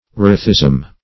Erethism \Er"e*thism\, n. [Gr.